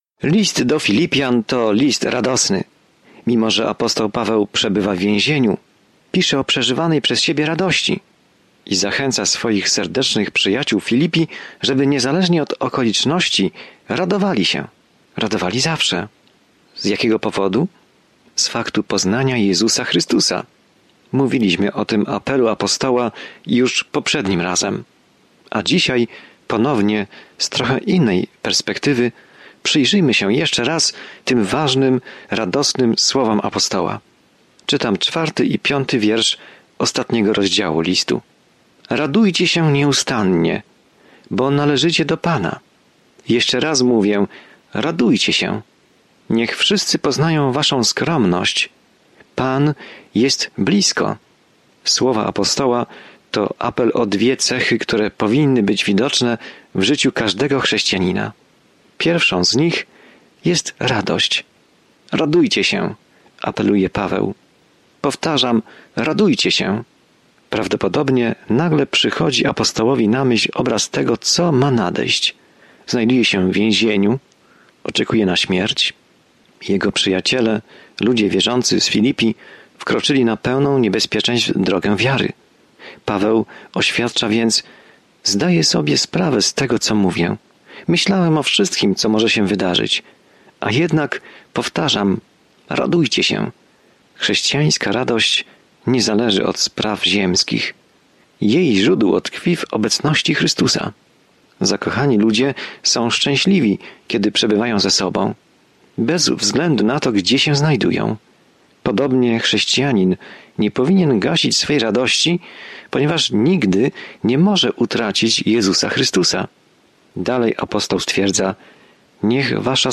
Pismo Święte Filipian 4:4-9 Dzień 14 Rozpocznij ten plan Dzień 16 O tym planie To podziękowanie skierowane do Filipian daje im radosną perspektywę na trudne czasy, w których się znajdują, i zachęca ich, aby pokornie przez nie przejść razem. Codziennie podróżuj przez List do Filipian, słuchając studium audio i czytając wybrane wersety słowa Bożego.